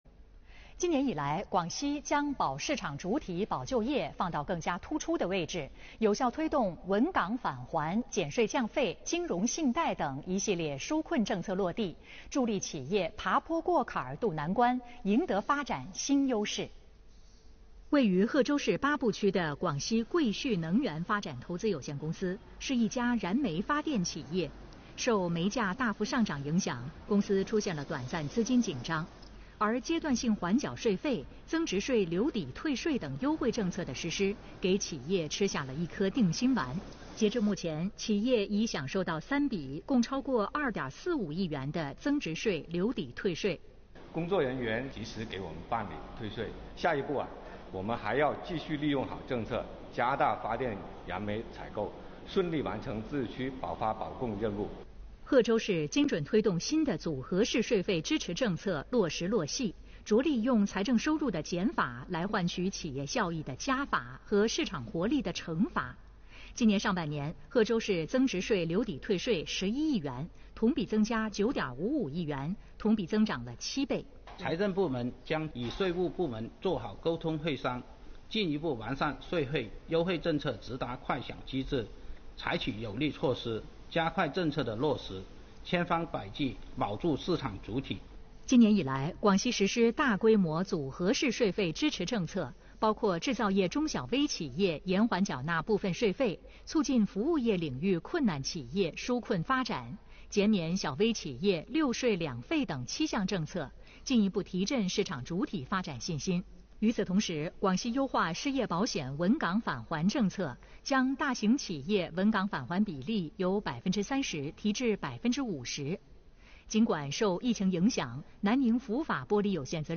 来源：广西广播电视台新闻频道